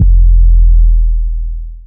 LexLuger808.wav